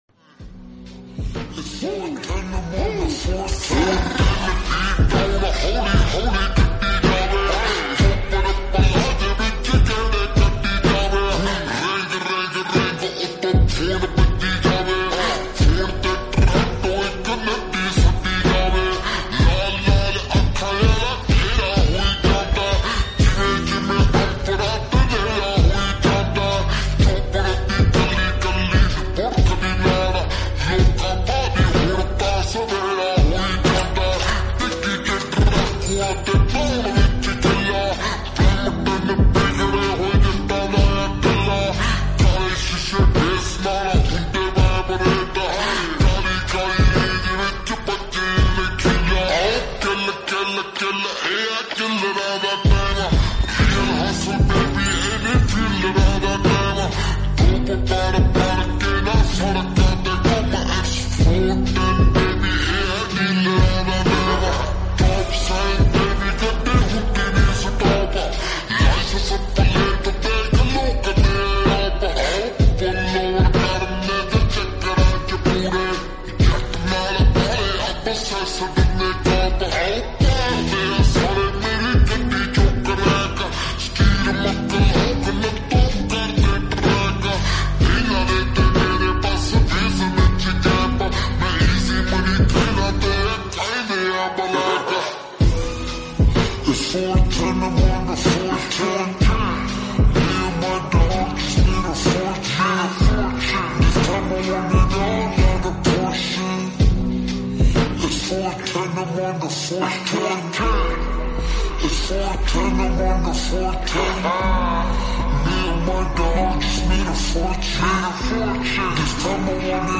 PUNJABI